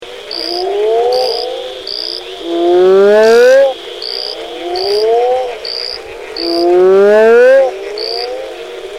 Common names: Mexican Burrowing Frog / Middle American Burrowing Toad
Males greatly inflate their bodies when calling, and eggs are laid singly and sink to the bottom where they later coalesce to form large clutches.